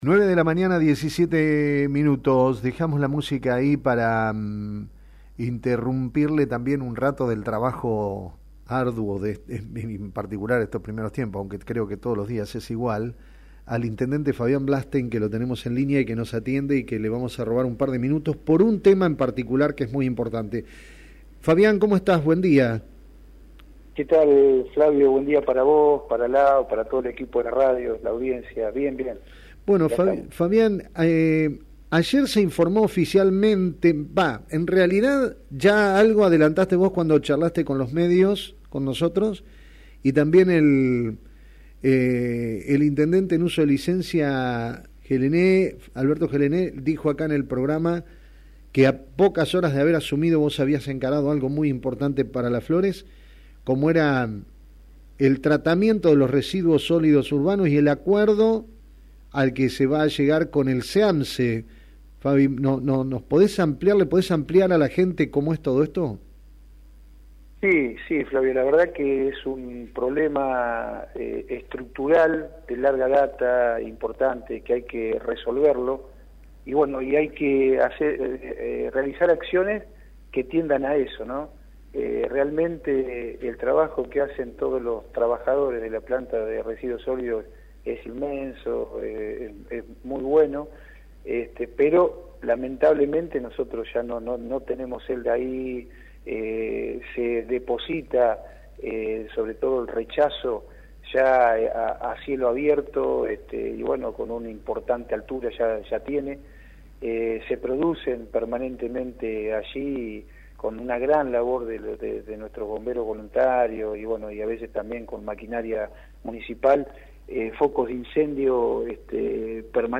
Así lo expresó el intendente municipal, Fabián Blanstein, esta mañana en Frecuencia Personal haciendo referencia al acuerdo entre el ejecutivo local y la empresa CEAMSE (Coordinación Ecológica Área Metropolitana Sociedad del Estado) para el traslado del rechazo (residuos no recuperables) de la planta local de residuos sólidos urbanos a ese predio.